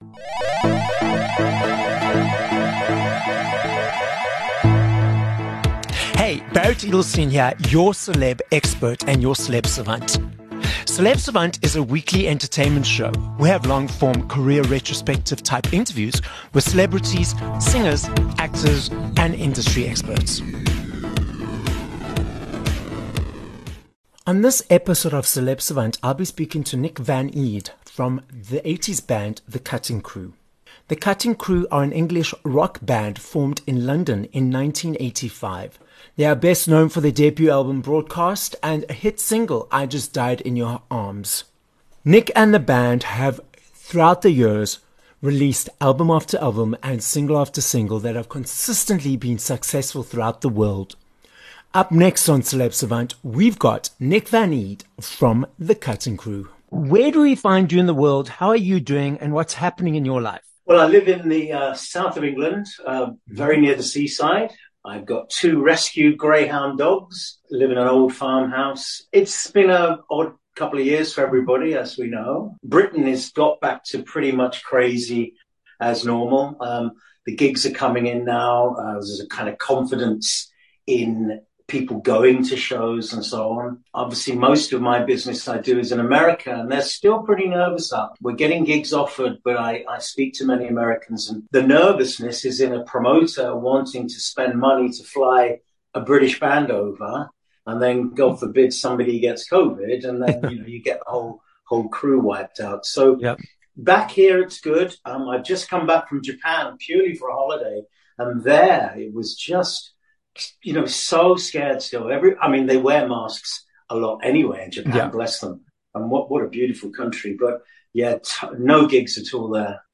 26 Mar Interview with Nick Van Eede from Cutting Crew